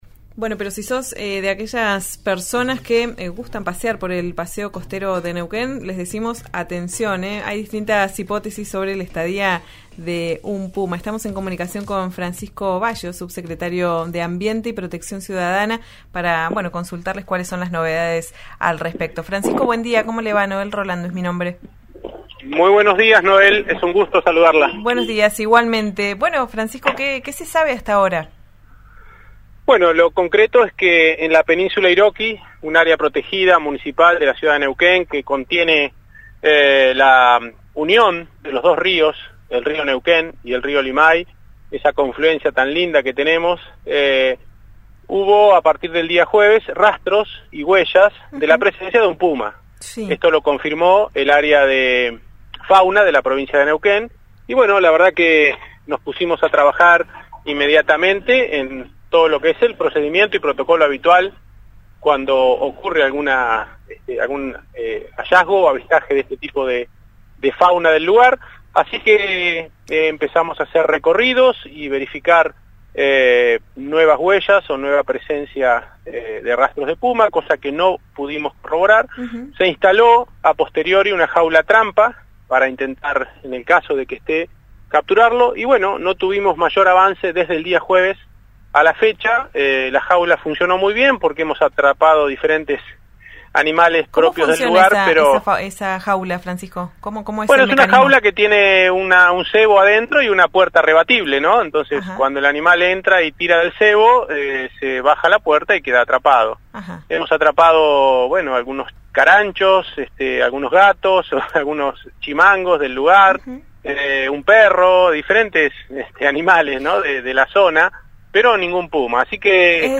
Escuchá a Francisco Baggio, subsecretario de Ambiente y Protección Ciudadana, en «Ya es tiempo» por RÍO NEGRO RADIO: